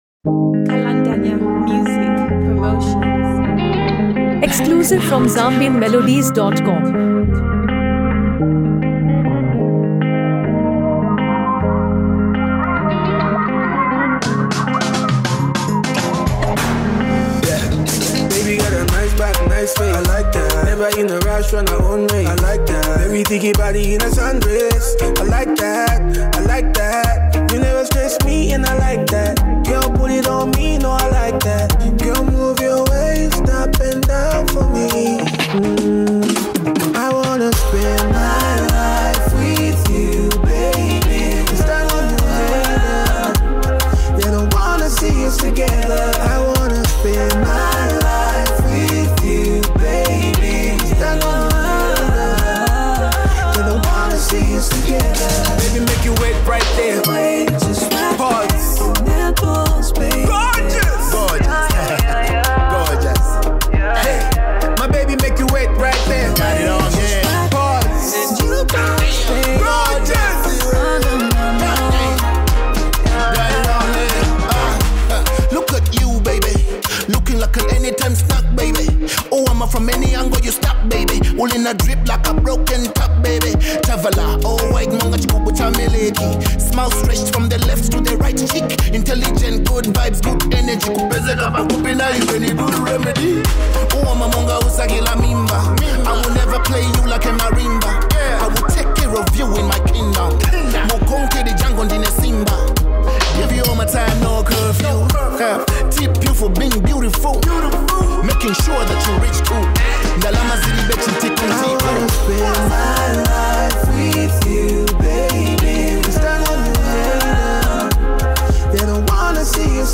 Genre: Afro-beats/ RnB